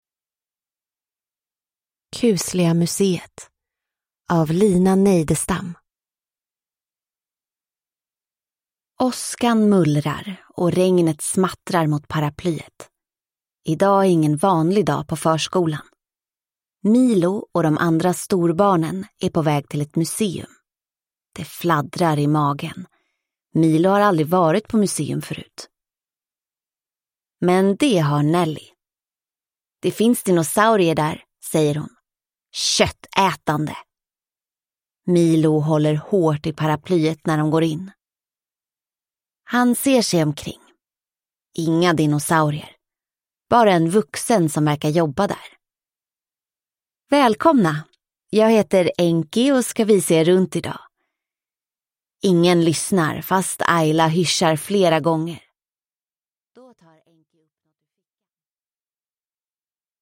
Kusliga museet – Ljudbok